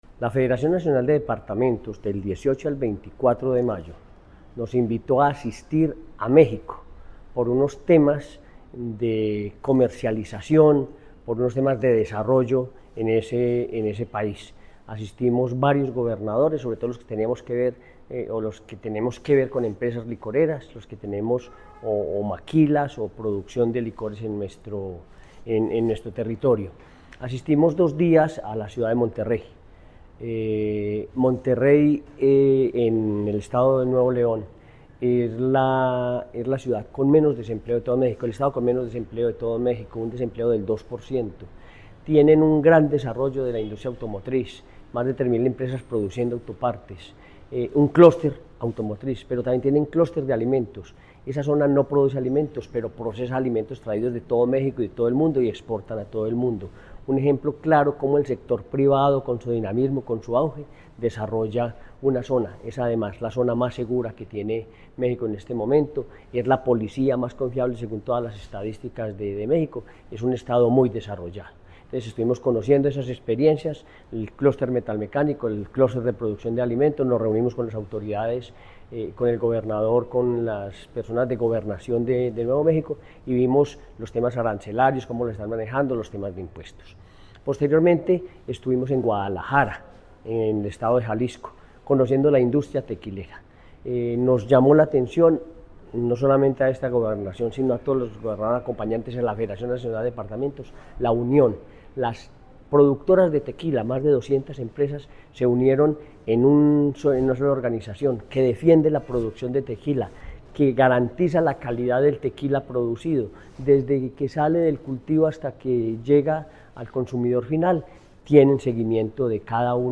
Henry Gutiérrez Ángel, gobernador de Caldas (Agenda en México)